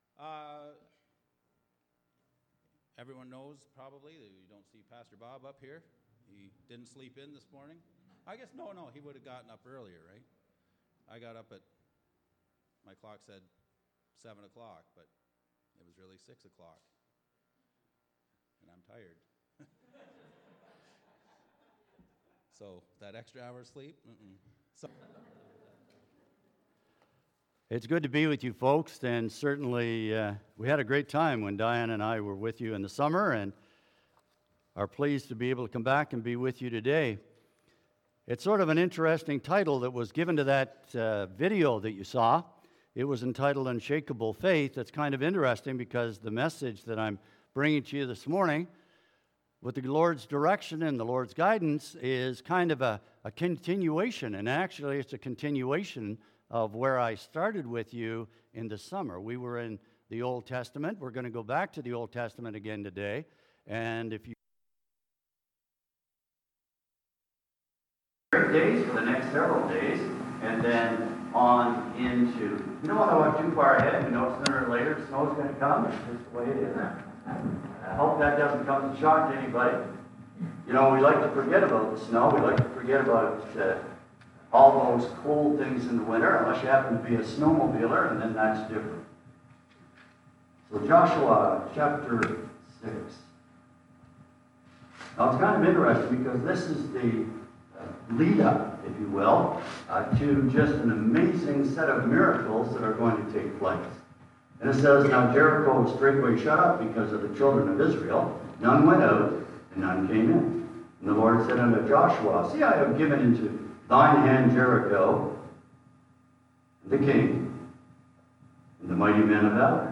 Psalm 22:1-21 Service Type: Sermon